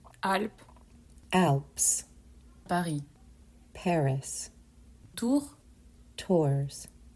Pay attention to the difference between the French and English pronunciations of these places in France.